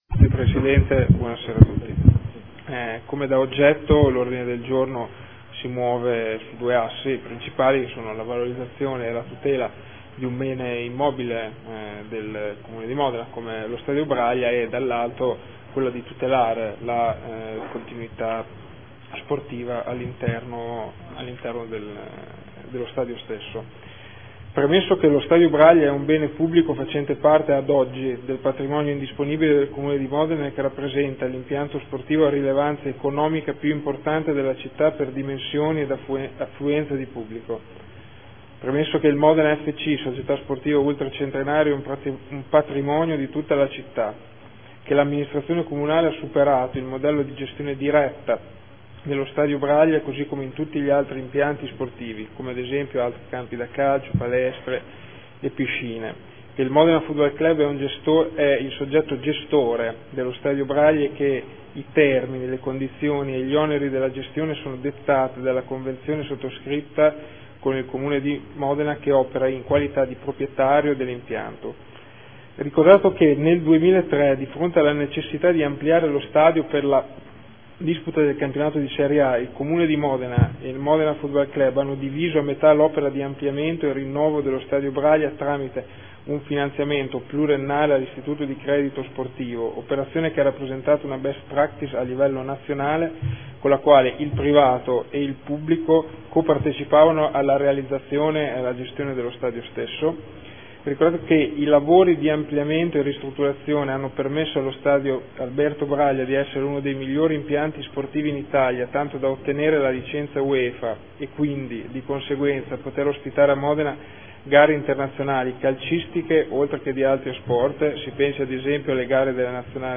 Andrea Bortolamasi — Sito Audio Consiglio Comunale
Seduta del 19/03/2015 Delibera. Convenzione per la gestione dello Stadio comunale Alberto Braglia al Modena FC S.p.a. – Modificazioni e prolungamento durata.